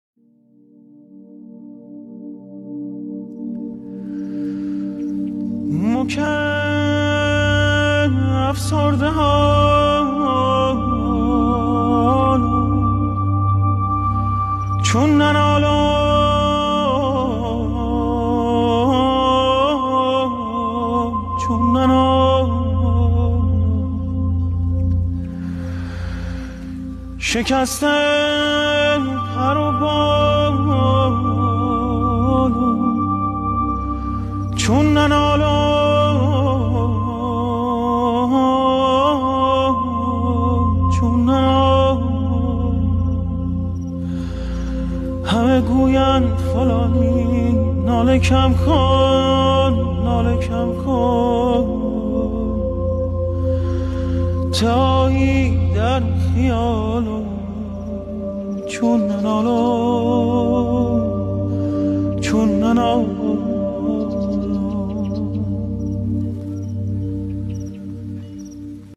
گروه موسیقی تلفیقی پاپ سنتی